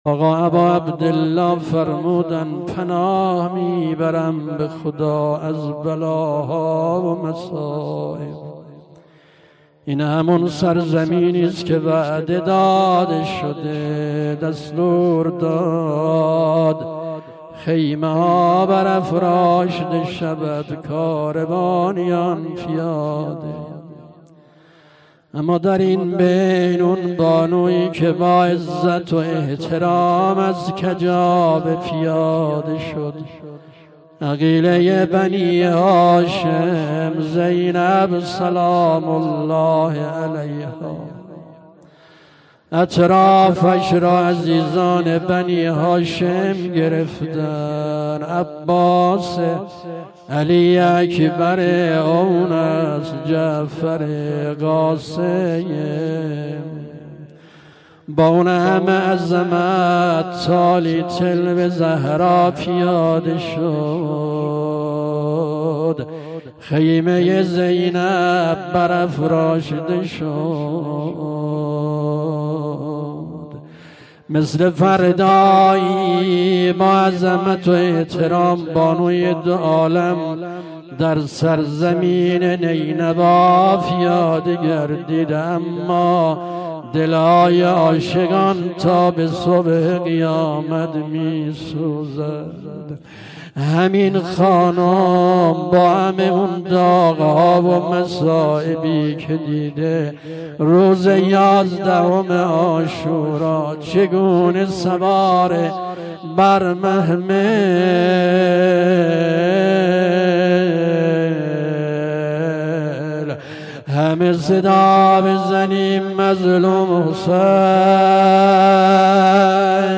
روضه
شب دوم محرم 93